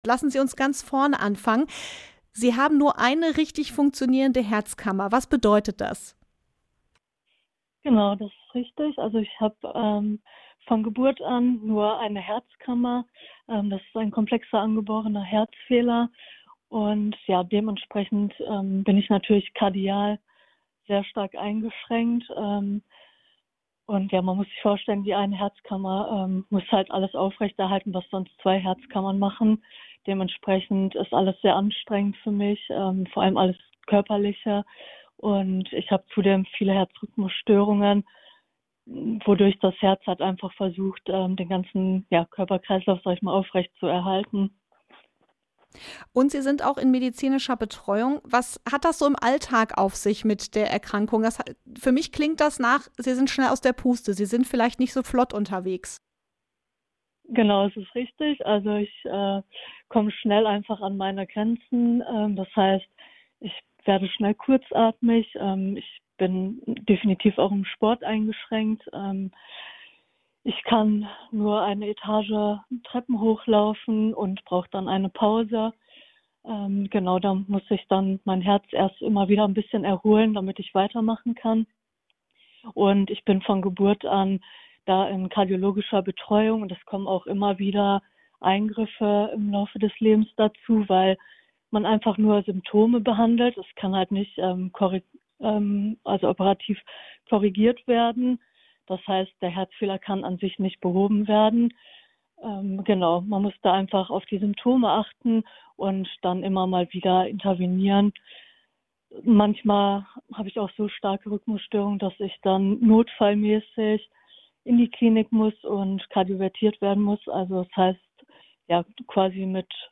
Über ihre Reise spricht sie mit der Redaktion von Himmel und Essen. Im Interview